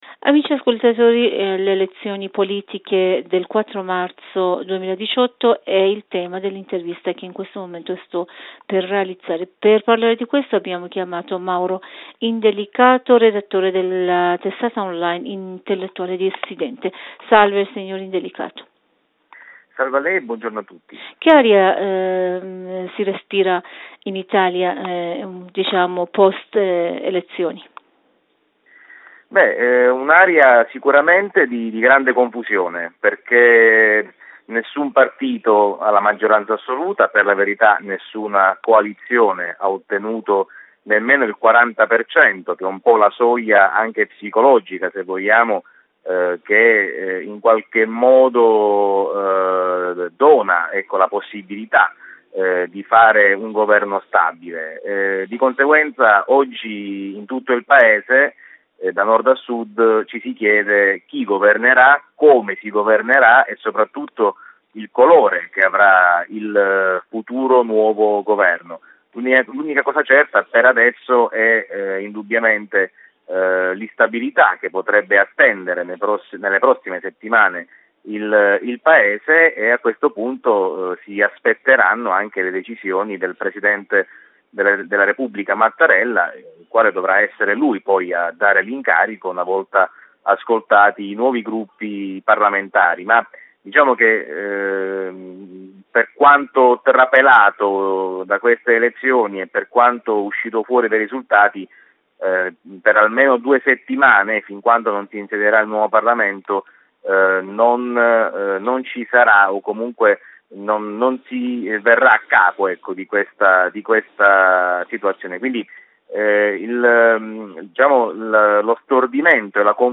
Per ascoltare la versione integrale dell'intervista potete cliccare qui sopra: